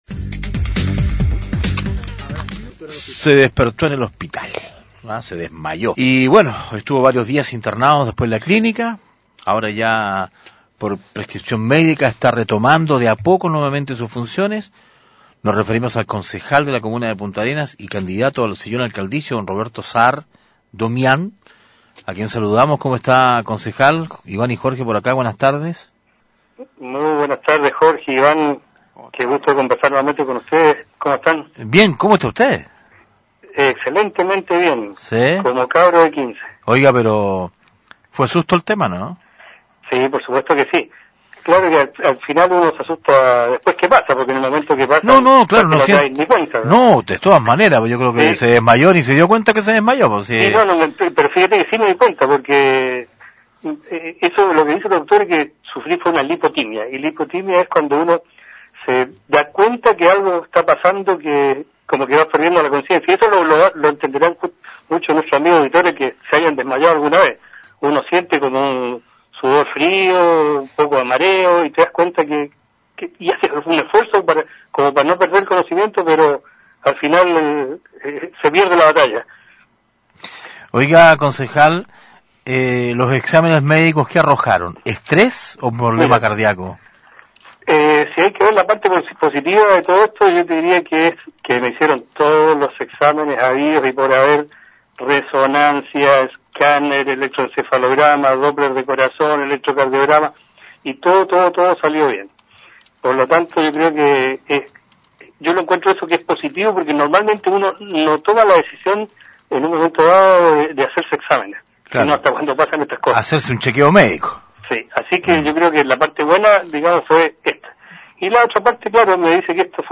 Entrevistas de Pingüino Radio - Diario El Pingüino - Punta Arenas, Chile
Roberto Sahr, concejal y cand. alcalde P.A.